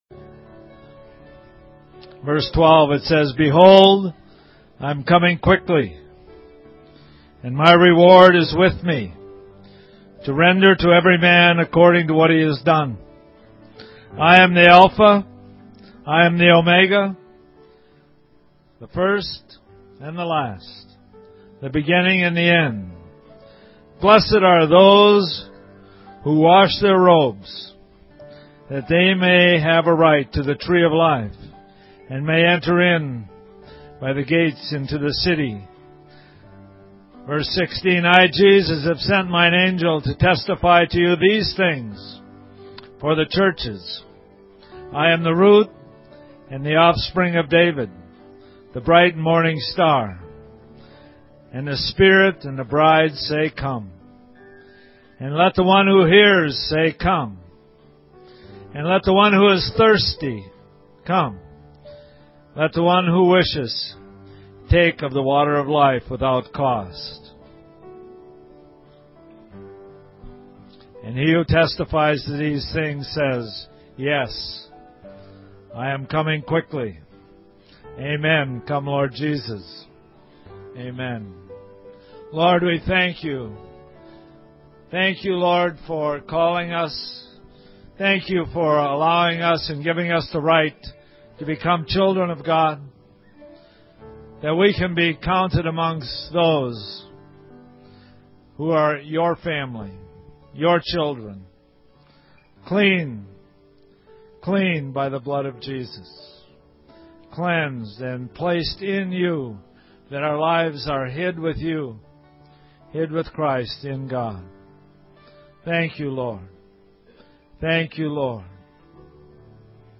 2012 Prophetic Conference Session 5